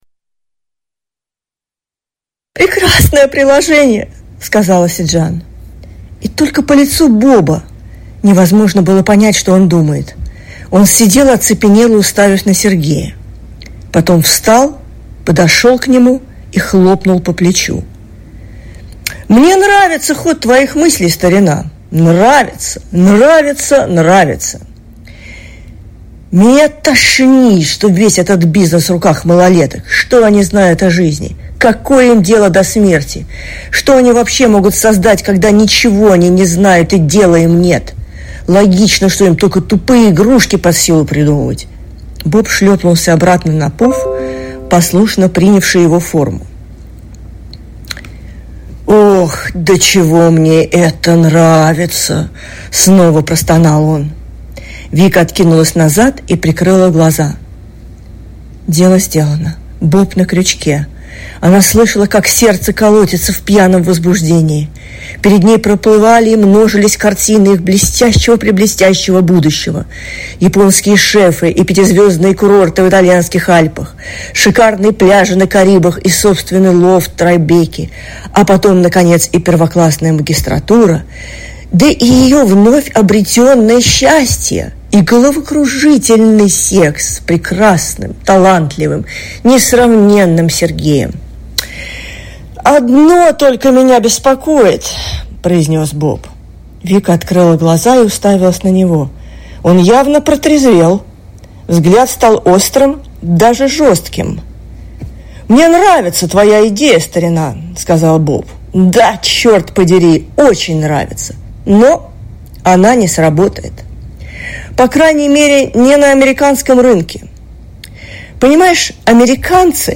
Читает автор